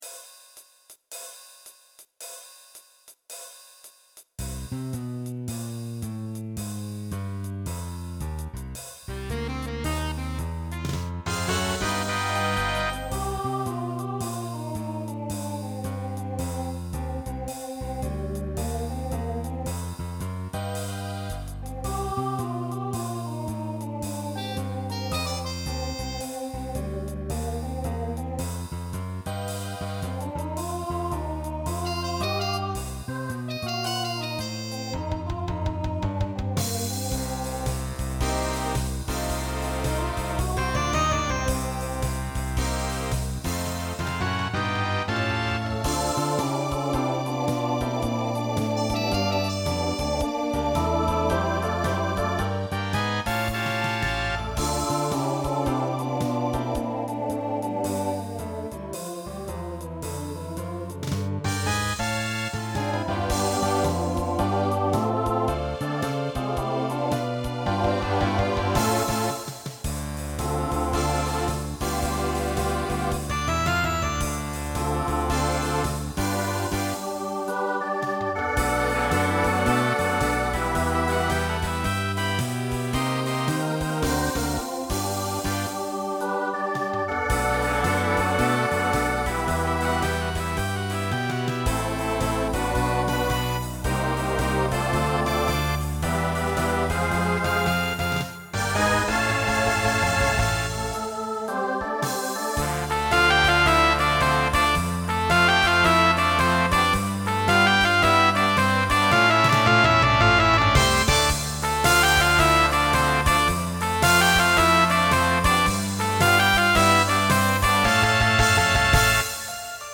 Jazz Arrangement
Voicing SATB Instrumental combo Genre Swing/Jazz
Mid-tempo